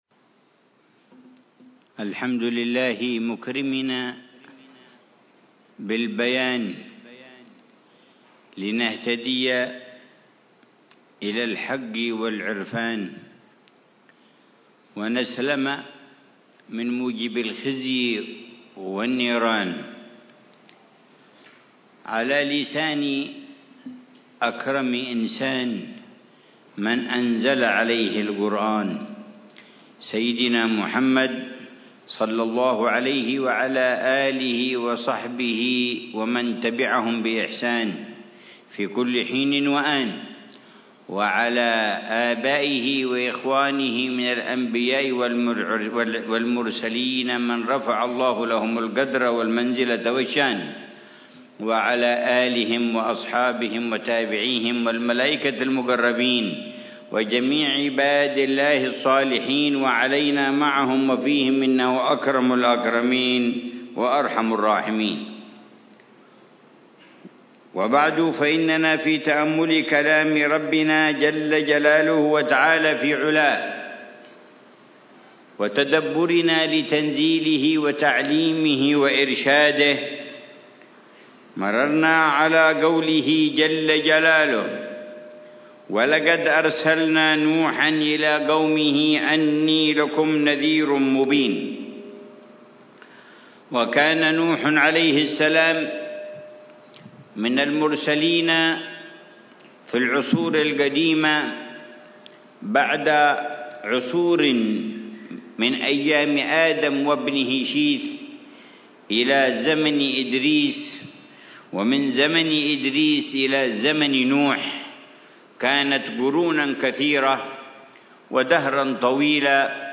تفسير الحبيب العلامة عمر بن محمد بن حفيظ للآيات الكريمة من سورة هود، من قوله تعالى: